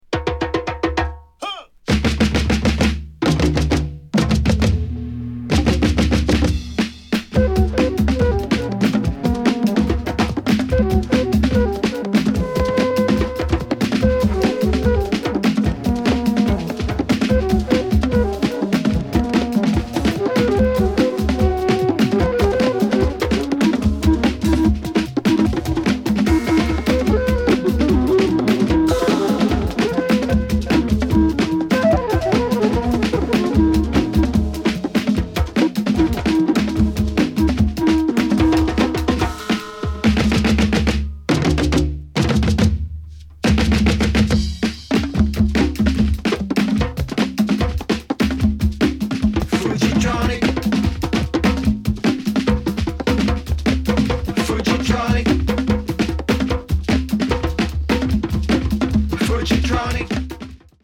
スリリングなアフロビート、リラクシンなジャズファンク、フリージャズ、マンボ